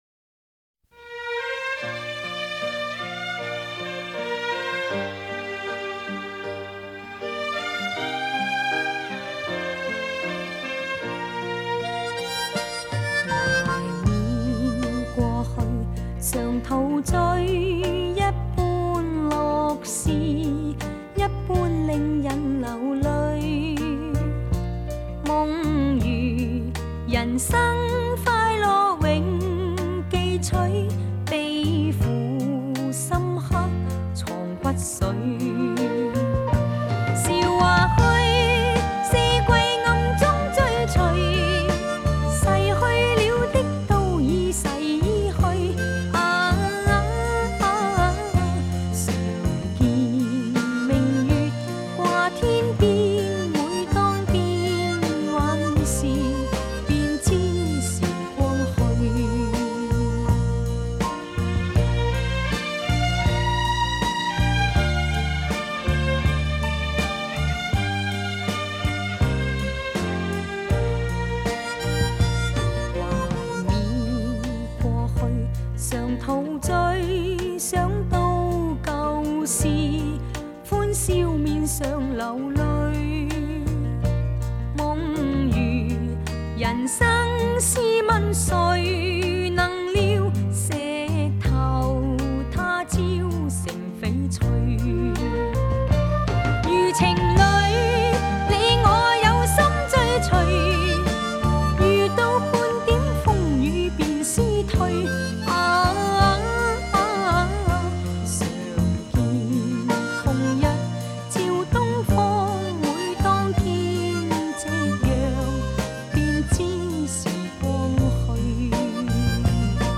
本碟采用美国SRS 3D音响技术
制作此超级立体HIFI 24K金碟